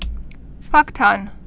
(fŭktŭn) Vulgar Slang